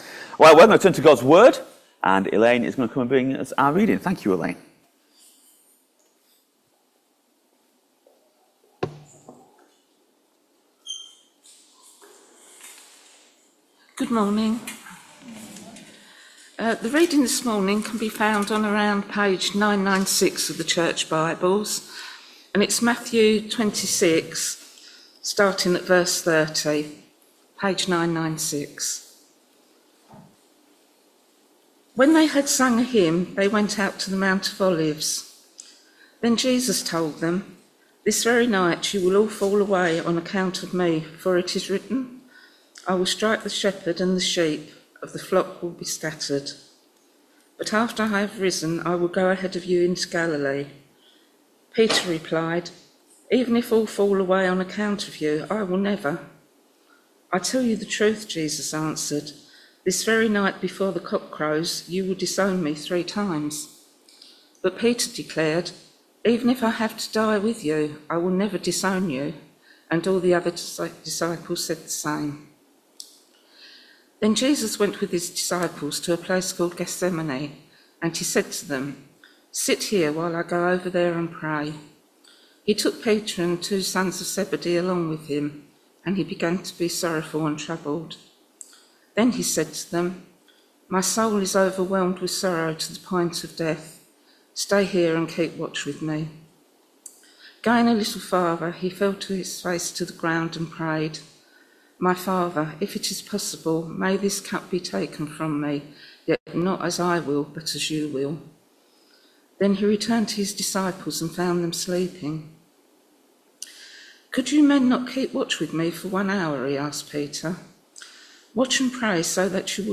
Matthew 26vv31-46 Service Type: Sunday Morning Service ma Topics